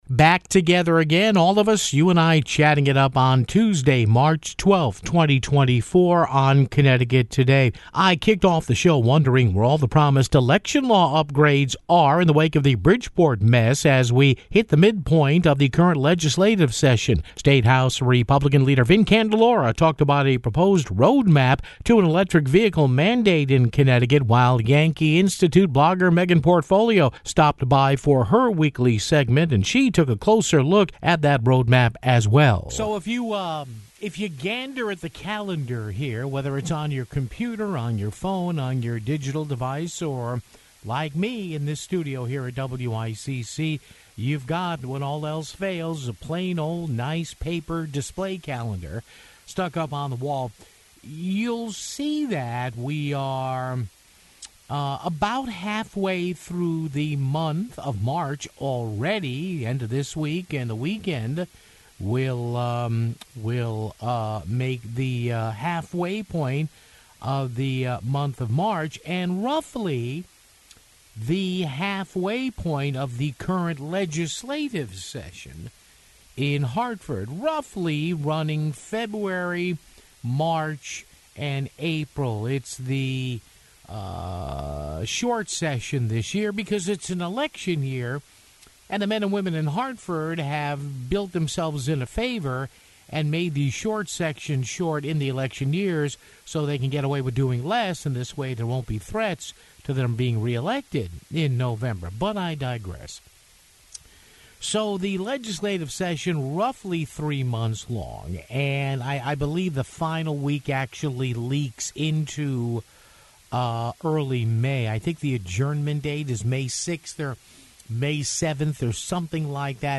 State House GOP leader Vin Candelora talked about a proposed "road map" to an electric vehicle mandate in Connecticut (12:54)